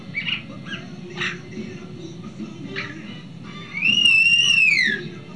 I can communicate with humans in many ways. If I want attention I can
scream very loudly.
SCREAM.WAV